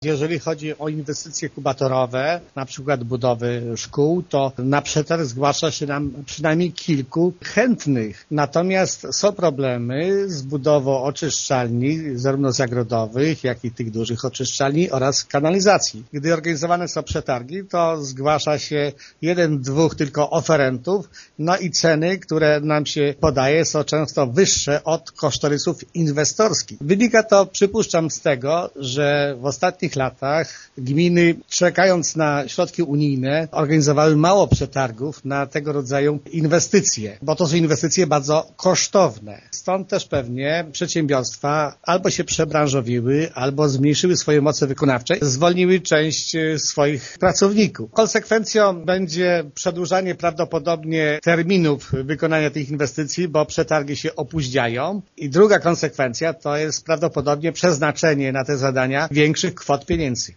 „Problem dotyczy zadań, związanych z ochroną środowiska” - przyznaje zastępca wójta wiejskiej Gminy Łuków Wiktor Osik: